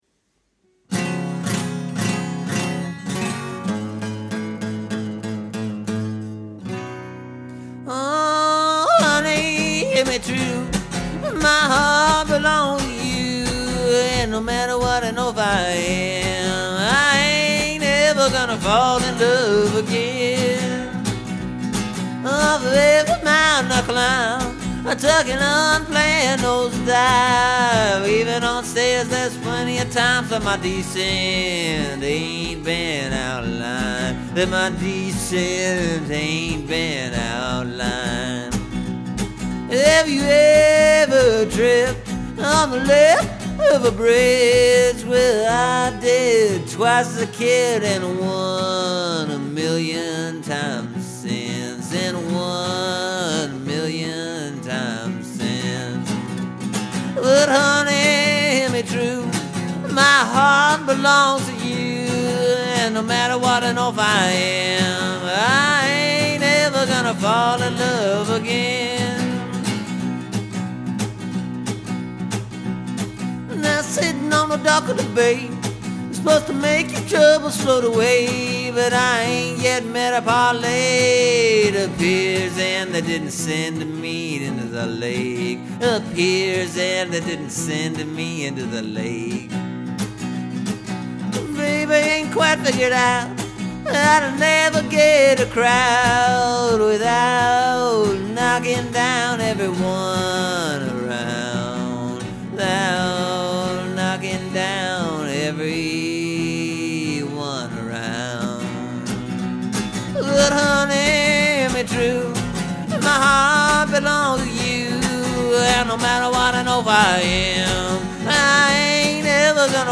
The chorus part (that starts off the song) I think is pretty much all done, I just couldn’t figure out the music for the other parts, though.
My songs usually are more repeating chorus line type jobs.